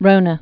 (rōnə, rŏnə)